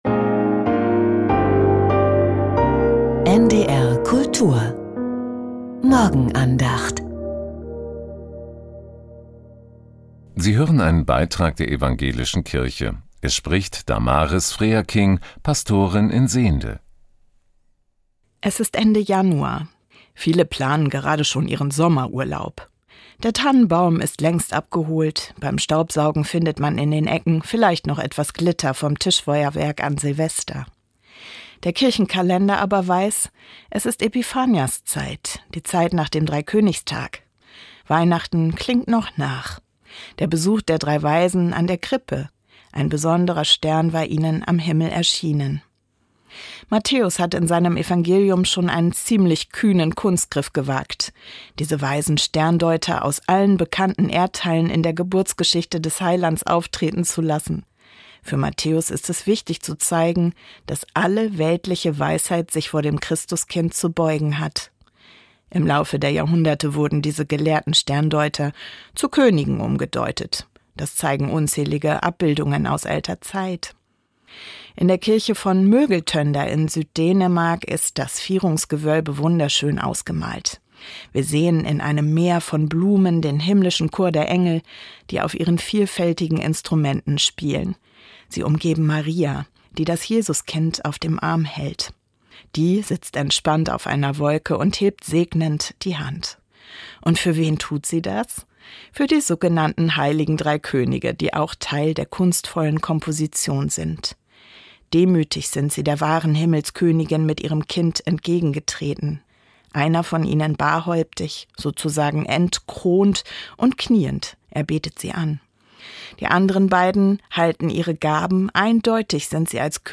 Ein besonderer Stern ~ Die Morgenandacht bei NDR Kultur Podcast